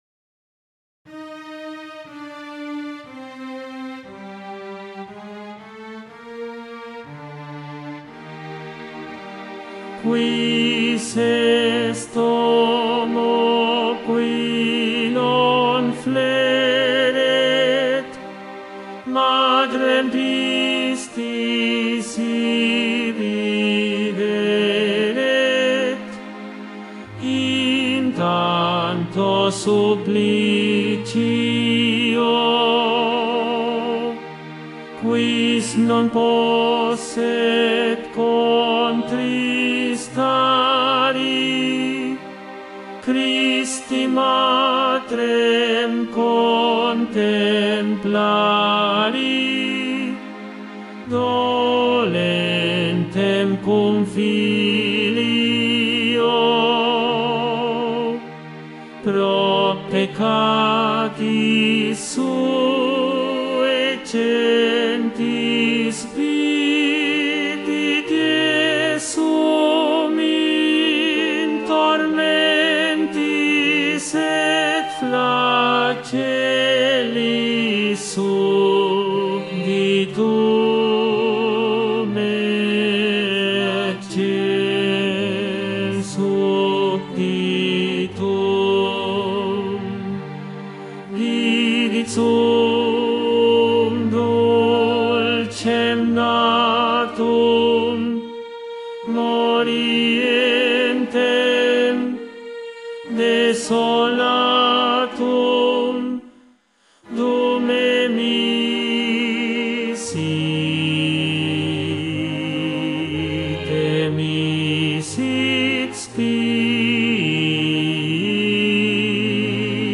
Tenor I
Mp3 Profesor